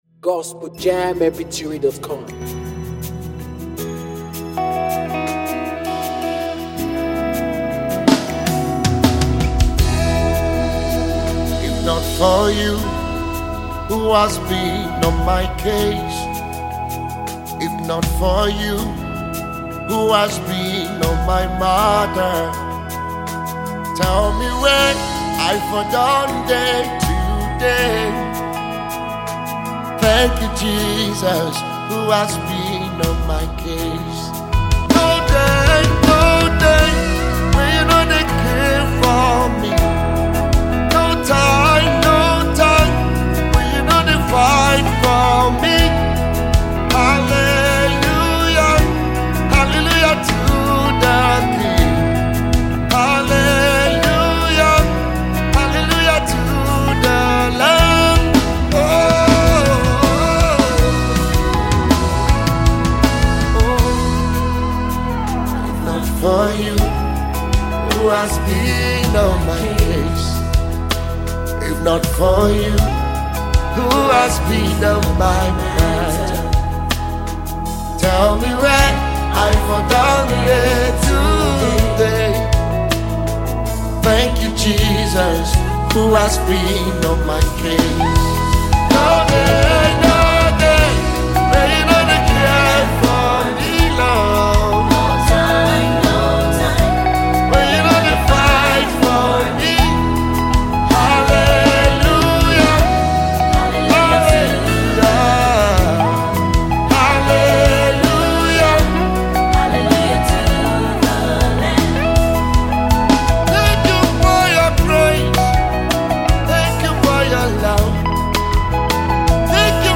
a Nigerian gospel singer
it was an inspirational song by the holy ghost